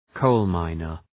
Προφορά
{‘kəʋl,maınər}